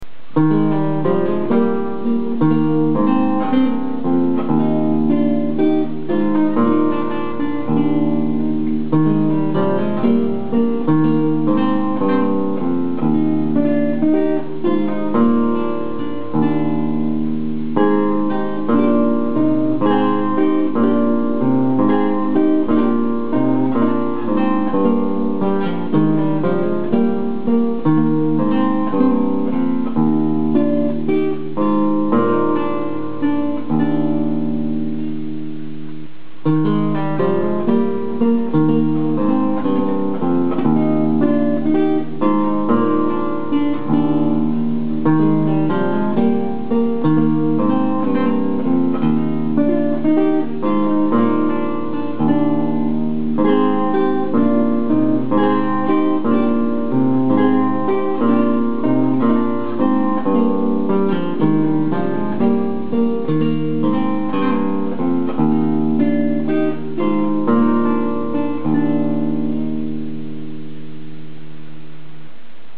Vaudeville
Anonymous French, 17th century Vaudeville.mp3